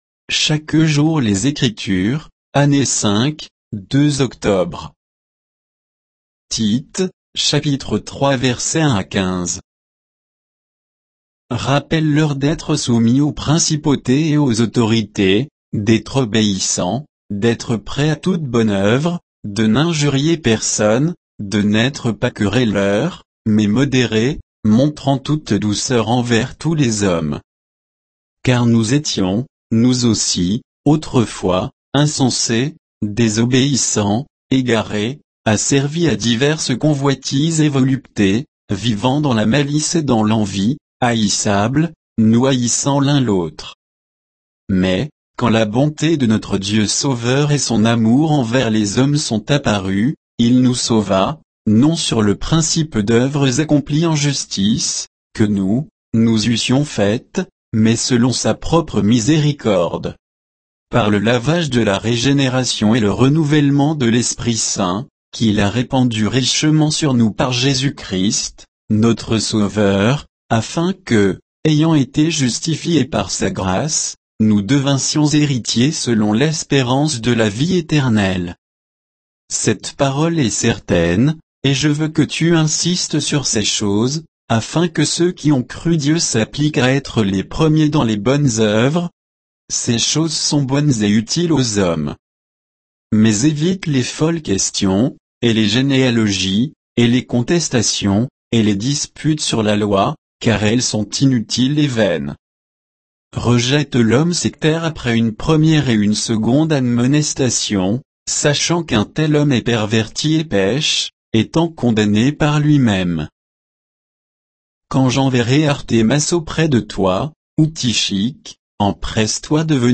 Méditation quoditienne de Chaque jour les Écritures sur Tite 3, 1 à 15